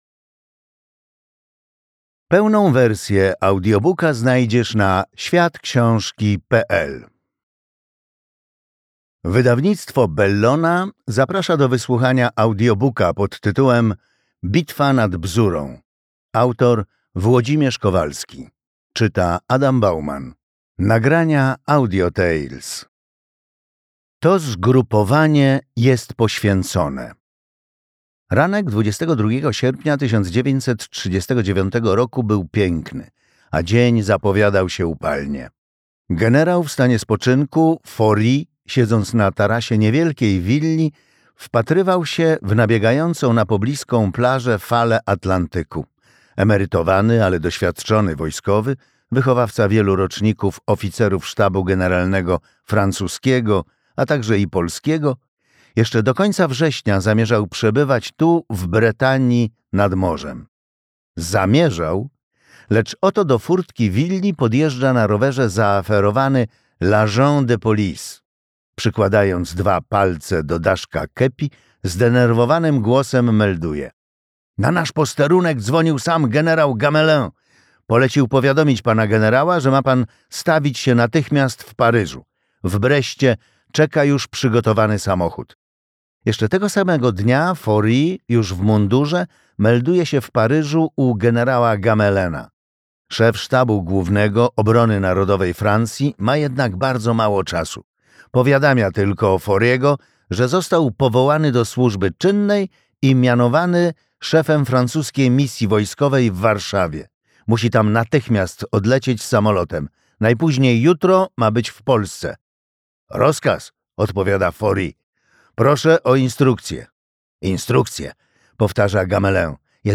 Audiobook Bitwa nad Bzurą, Kowalski Włodzimierz.